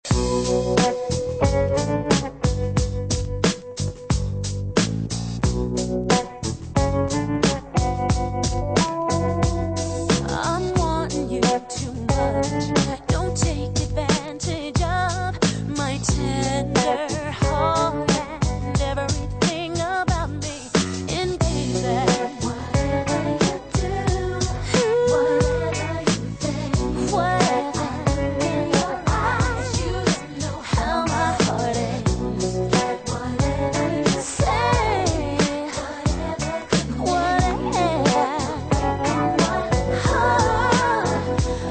H I P   H O P    S O U L     F U N K      R N B